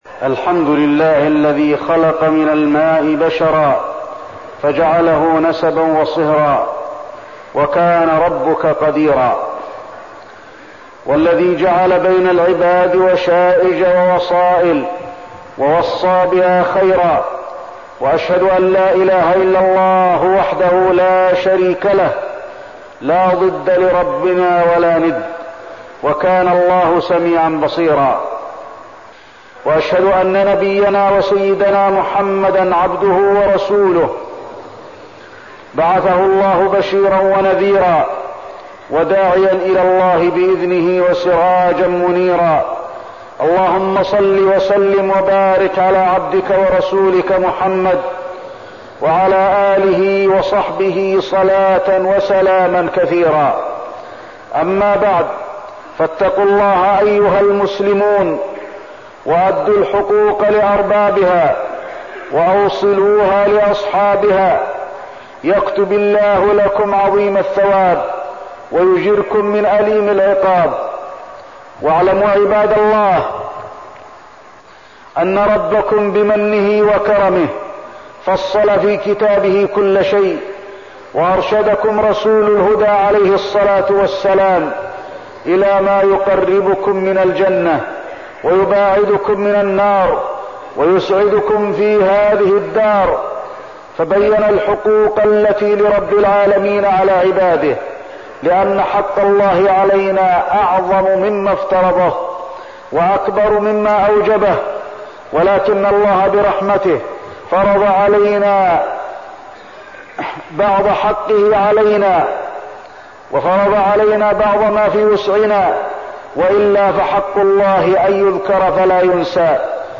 تاريخ النشر ٢٠ شوال ١٤١٤ هـ المكان: المسجد النبوي الشيخ: فضيلة الشيخ د. علي بن عبدالرحمن الحذيفي فضيلة الشيخ د. علي بن عبدالرحمن الحذيفي صلة الرحم